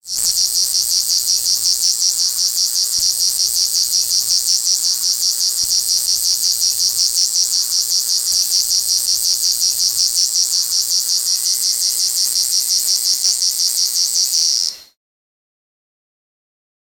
Species: Becquartina versicolor